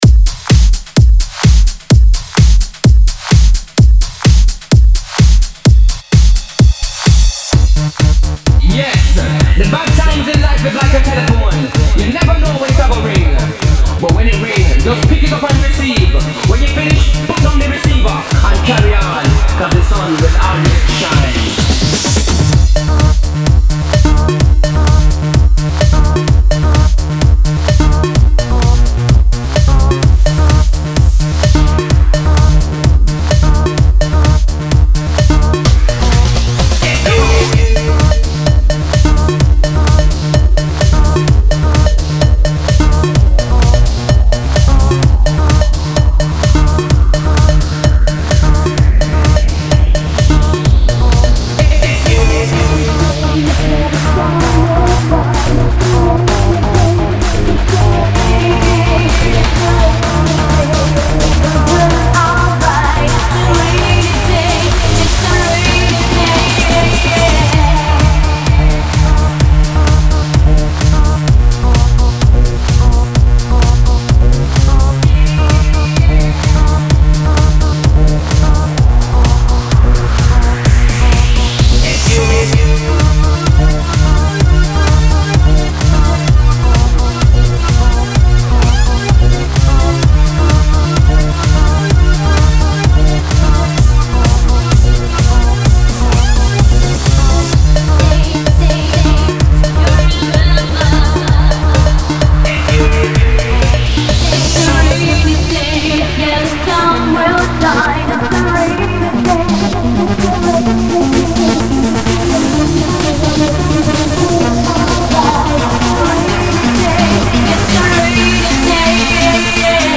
(Ремикс) PR